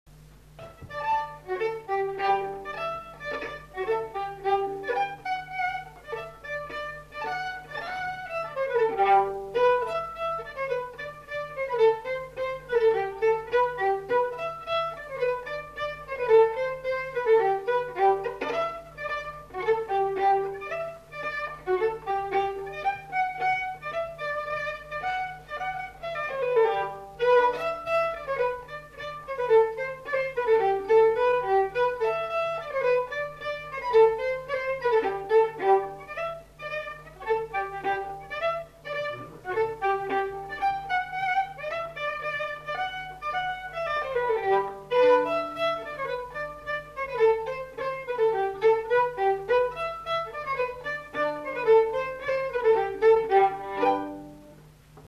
Aire culturelle : Gabardan
Genre : morceau instrumental
Instrument de musique : violon
Danse : polka des bébés